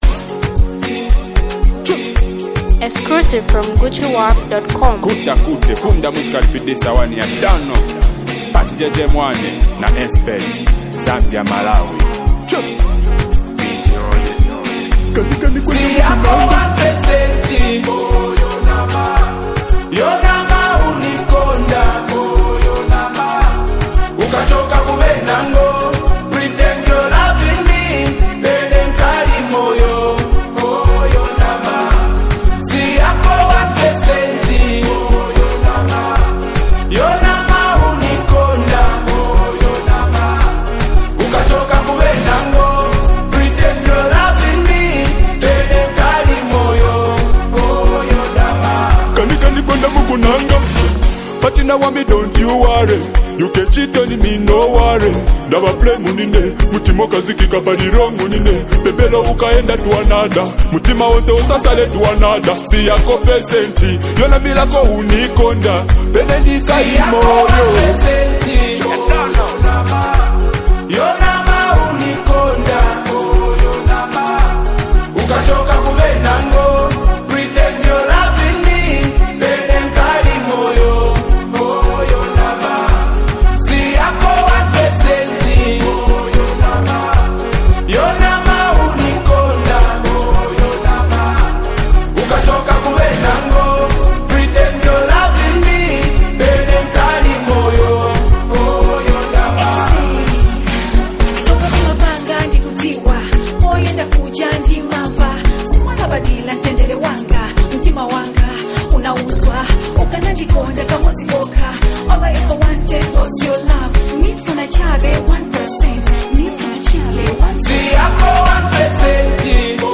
Zambia’s dynamic reggae and dancehall sensation